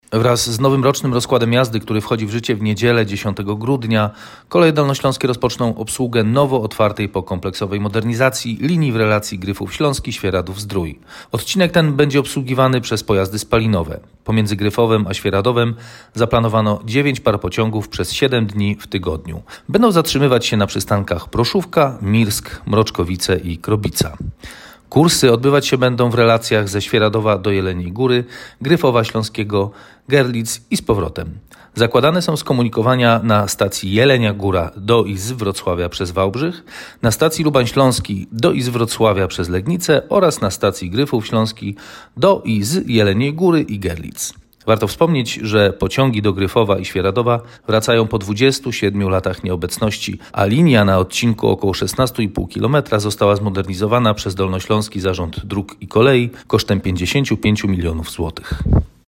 Na starcie zaplanowano 9 par pociągów przez 7 dni w tygodniu. Mówi